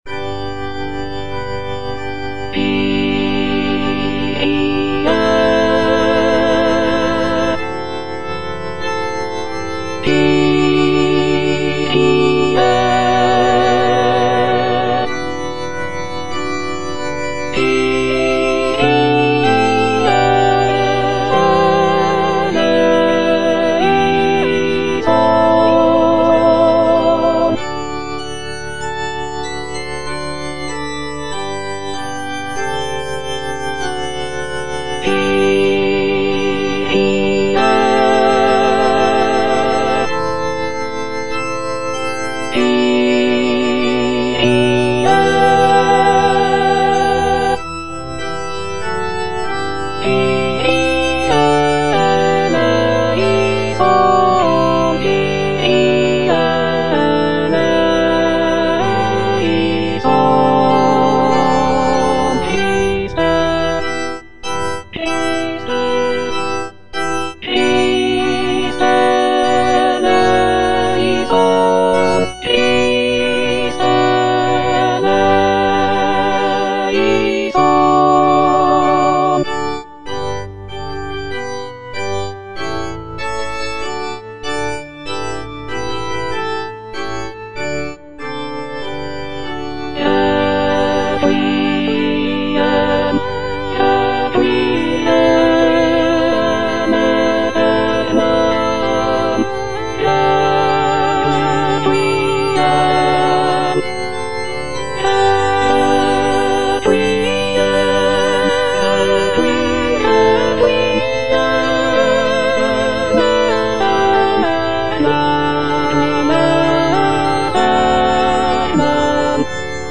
Alto (Emphasised voice and other voices) Ads stop
is a sacred choral work rooted in his Christian faith.